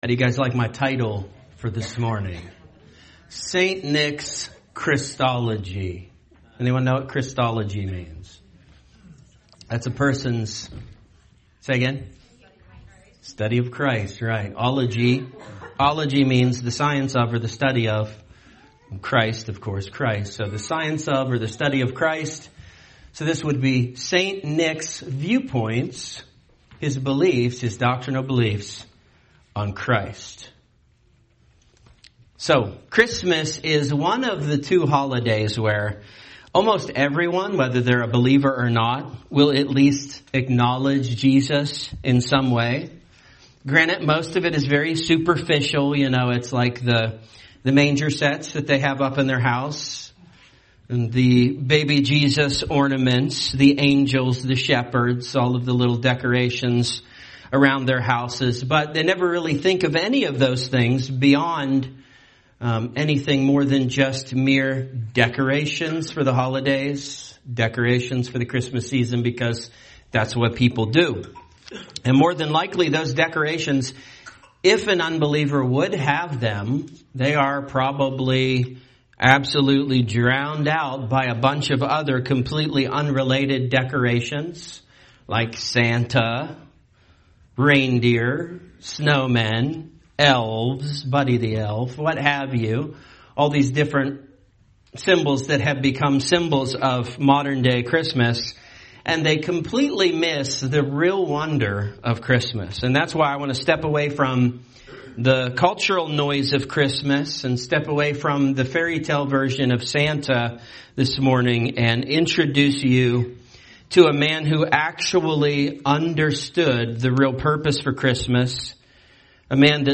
A message from the topics "Christmas."